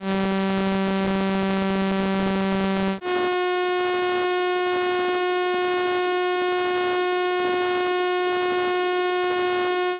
Stimmtöne Arkadische Botschaften I mp3
micro_guitar3XI.mp3